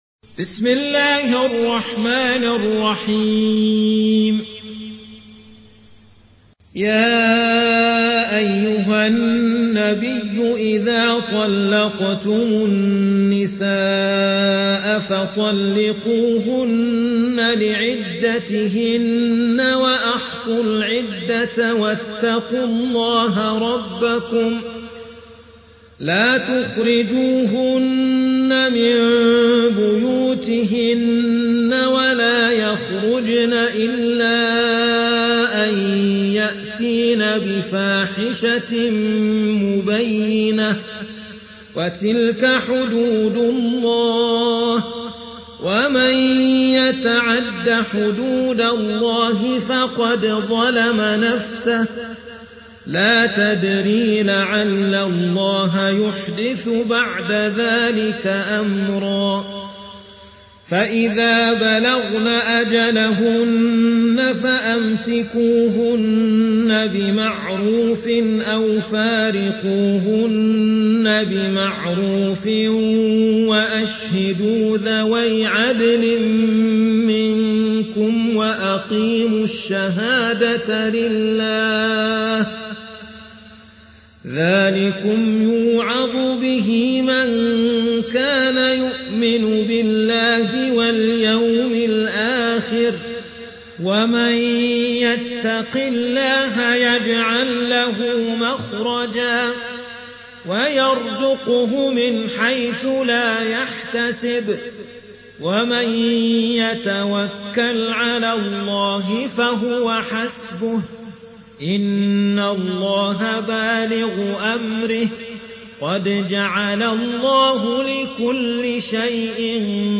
تحميل : 65. سورة الطلاق / القارئ عبد الهادي كناكري / القرآن الكريم / موقع يا حسين